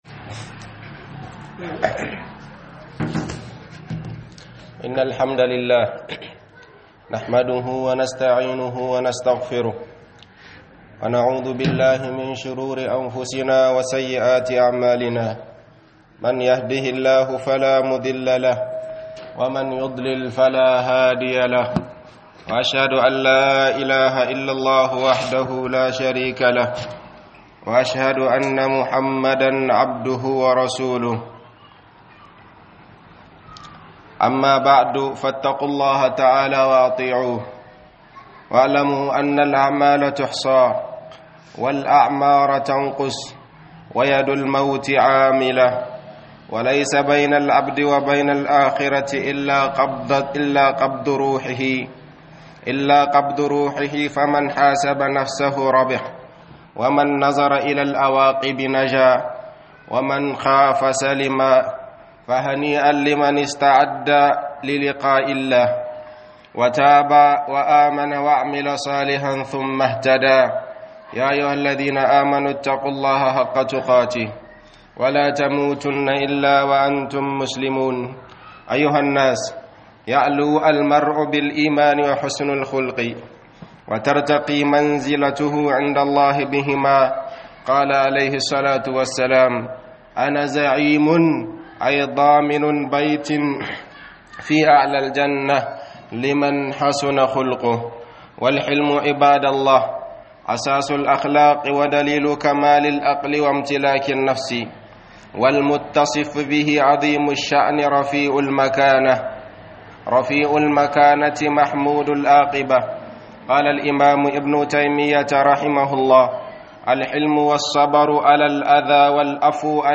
joriya da hakuri (1) - HUDUBA